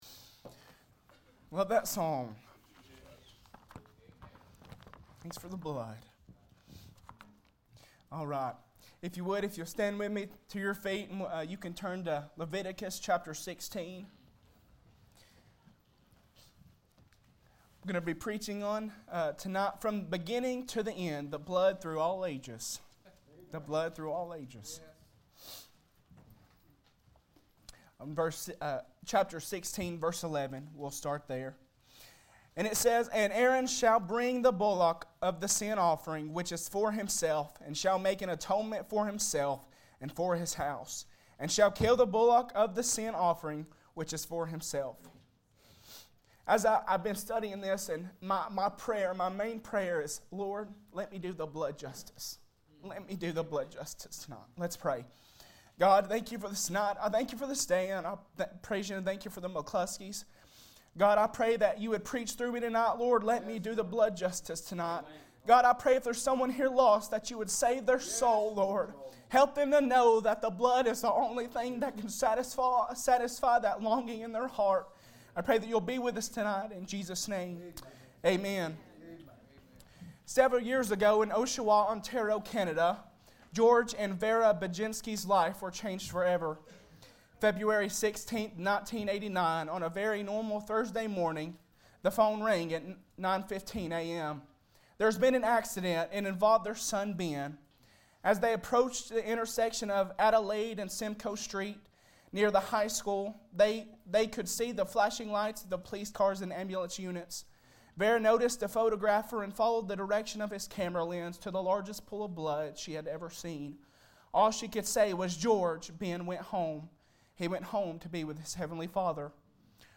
Service Type: Sunday Night